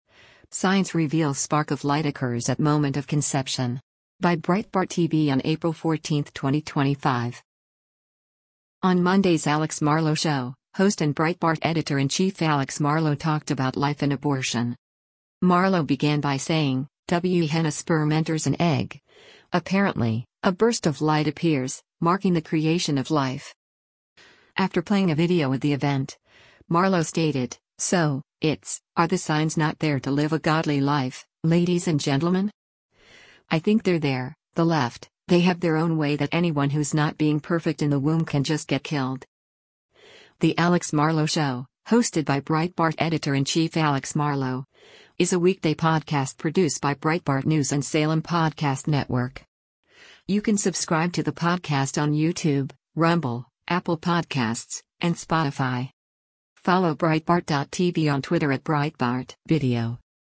On Monday’s “Alex Marlow Show,” host and Breitbart Editor-in-Chief Alex Marlow talked about life and abortion.